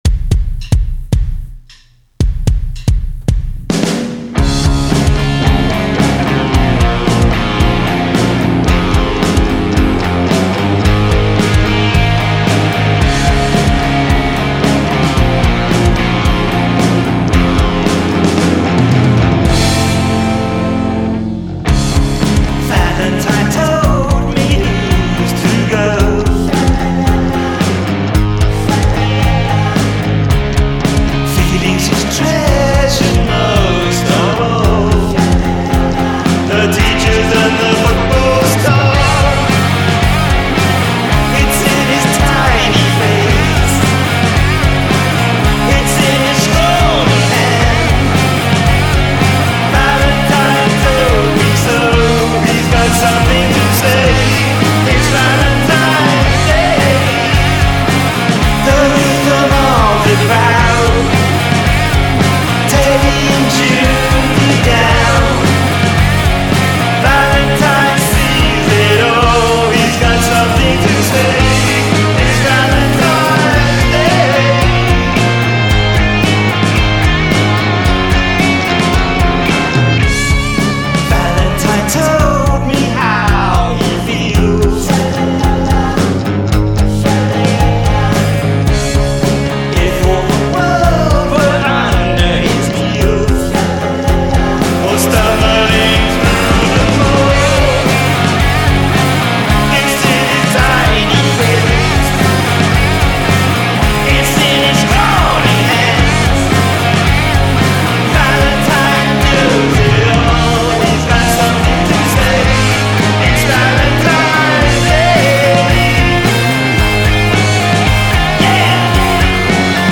punk
bright and breezy with its layered guitar hooks
end section guitar breakdown